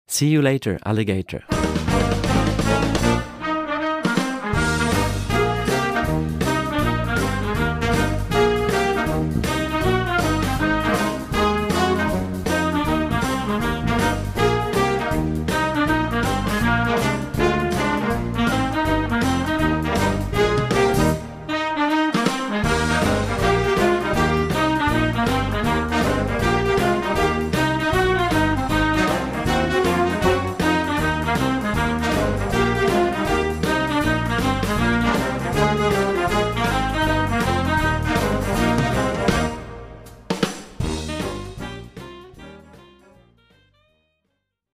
Gattung: Jugendblasorchester
Besetzung: Blasorchester